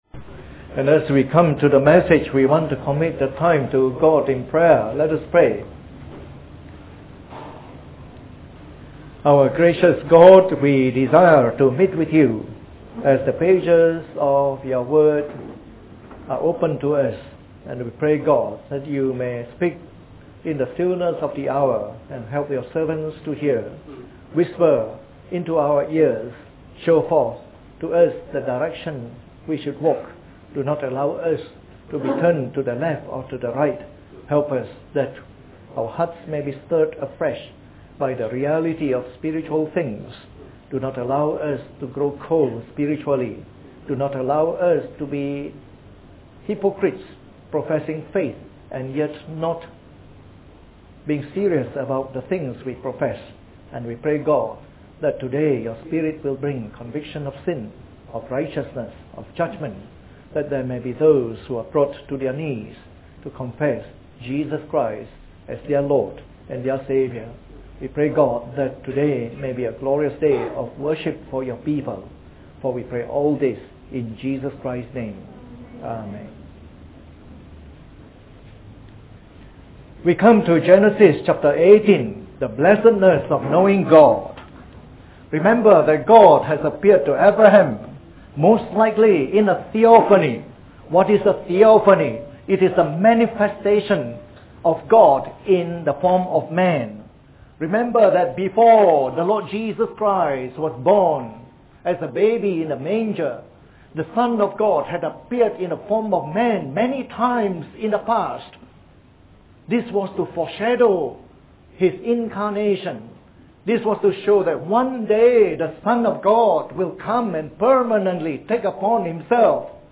Preached on the 16th of January 2011. Part of our series on the Book of Genesis delivered in the Morning Service.